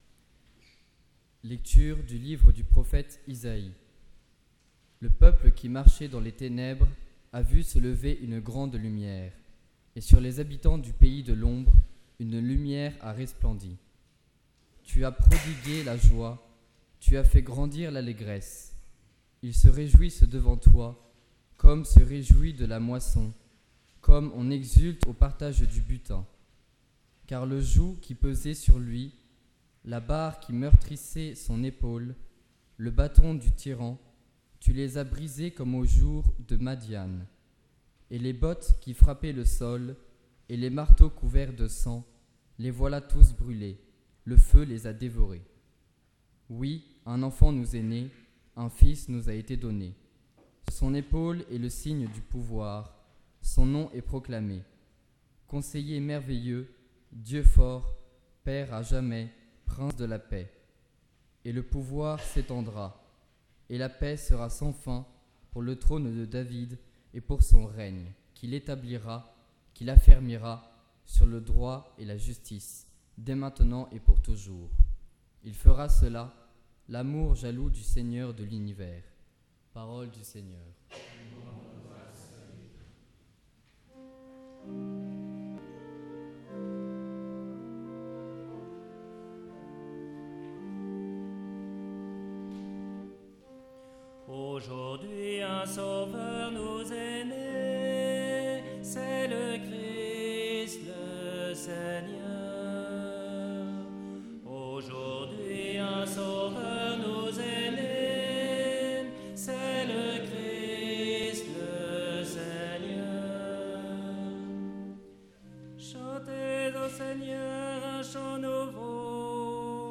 Parole de Dieu & Sermon – Nuit de Noël 2022
Parole-de-Dieu-Sermon-Nuit-de-Noel-2022.mp3